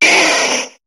Cri de Caninos dans Pokémon HOME.